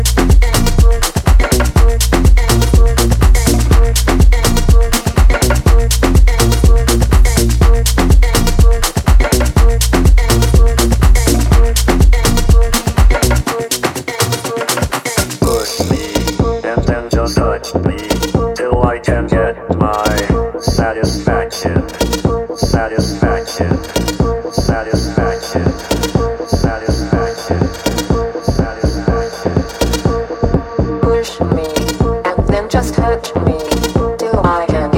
Dance
Жанр: Танцевальные